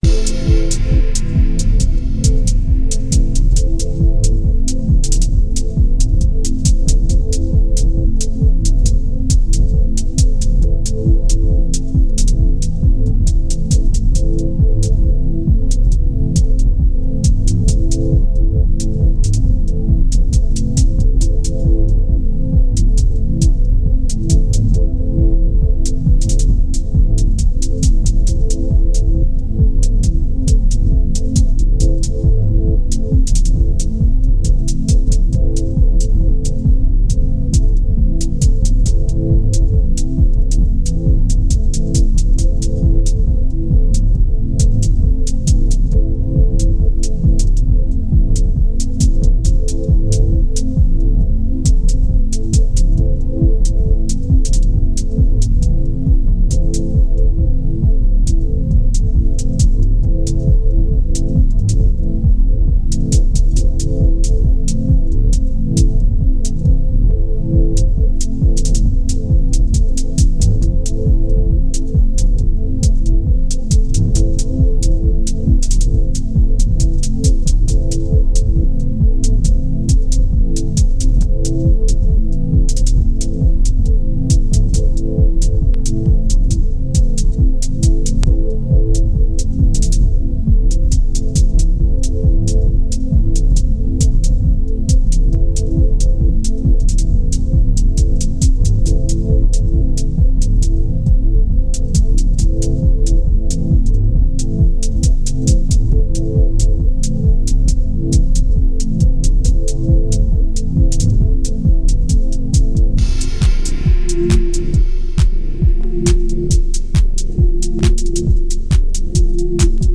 Here’s some techno I wrote this weekend.
It’s interesting I think because the unmastered version retains all of the subsonics, and sounds great on the correct system. The mastered version kills some of these in order to make it a little more friendly on low end systems.
I’m getting better at this FM synthesis.